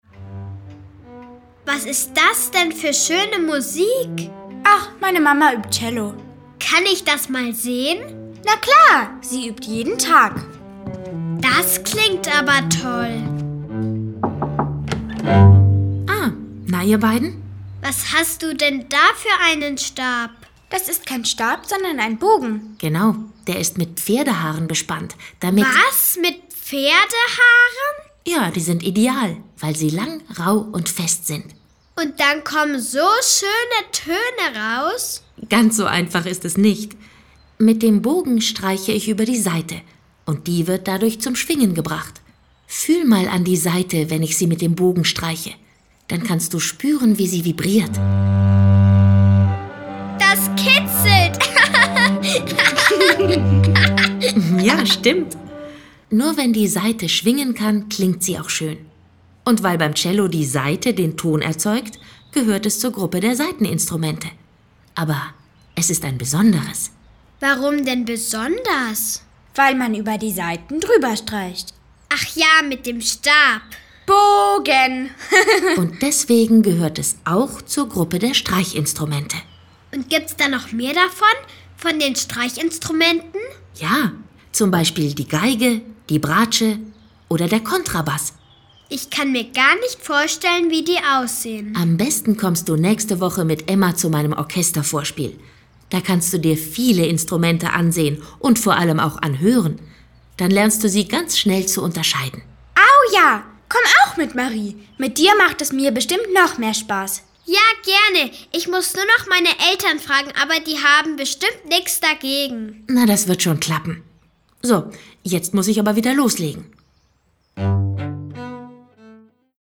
Tolle Lieder zum Mitsingen!